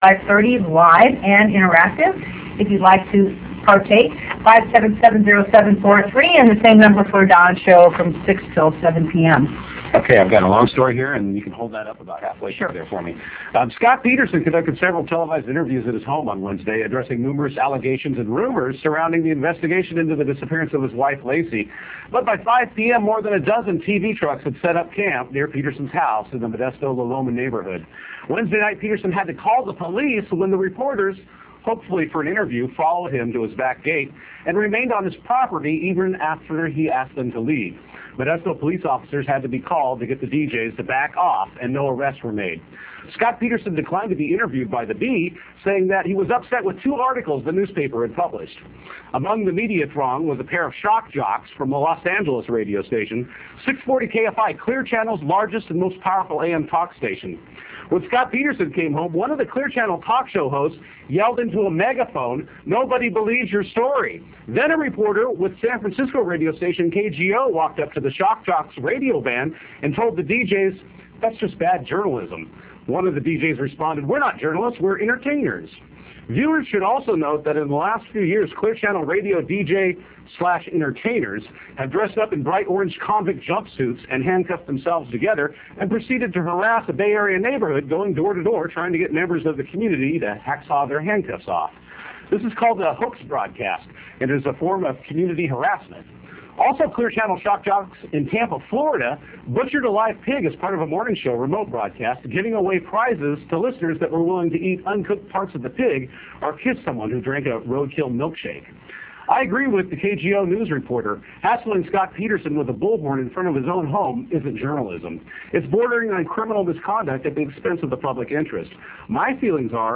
§news cast / small video
I backed up the direct action with a newcast
on our local low power TV station.